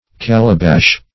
Calabash \Cal"a*bash\ (k[a^]l"[.a]*b[a^]sh), n. [Sp. calabaza,